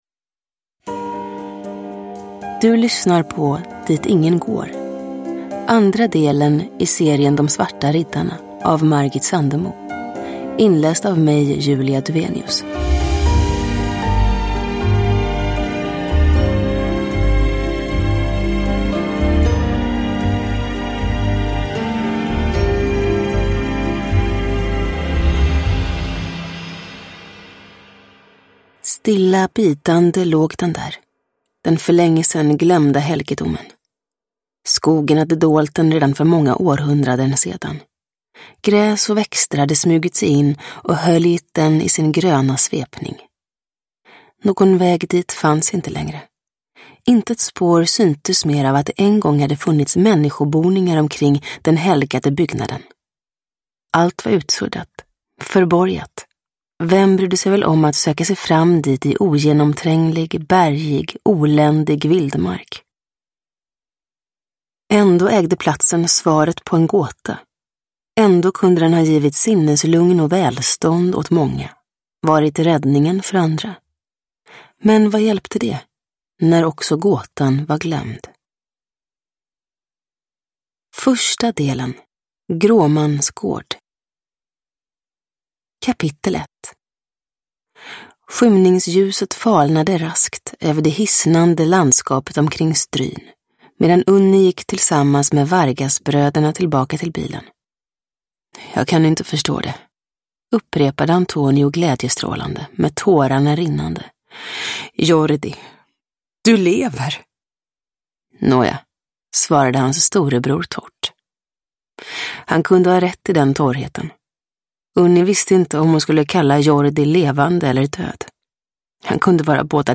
Dit ingen går – Ljudbok – Laddas ner
Uppläsare: Julia Dufvenius